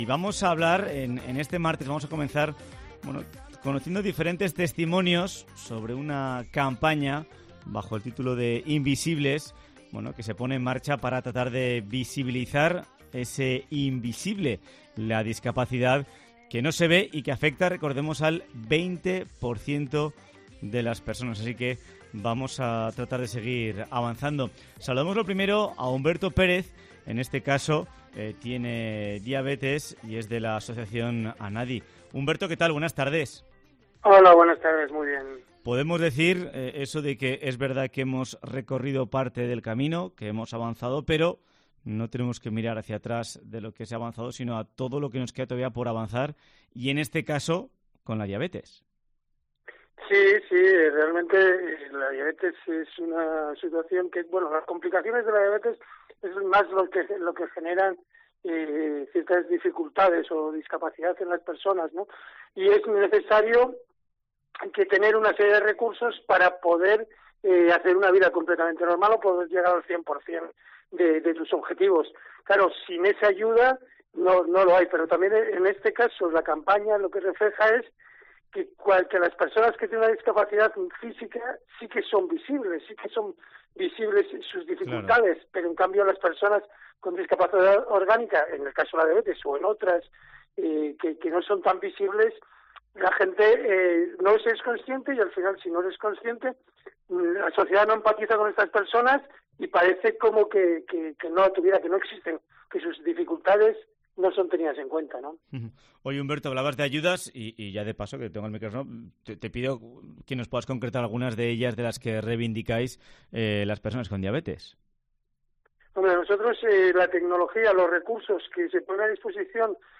En Cope Navarra, hablamos con varios protagonistas de estas discapacidades orgánicas.